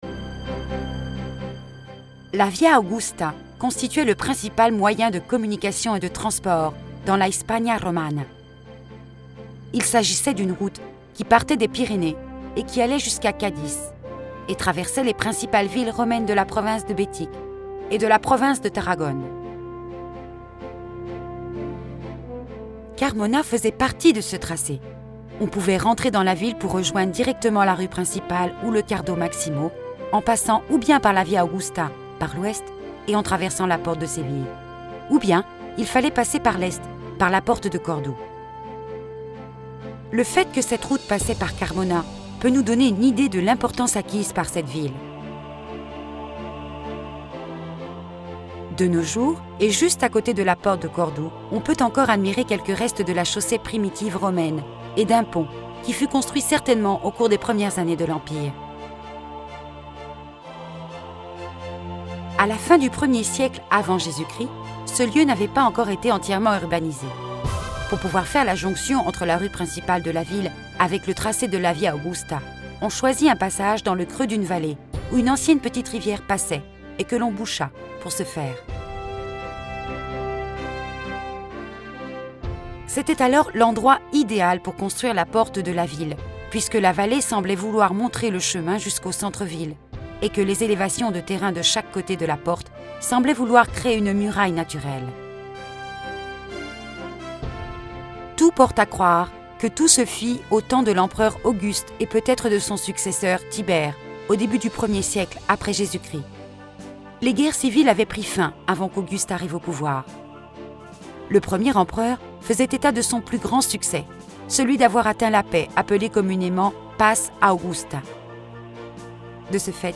Audioguides pour la visite de Carmona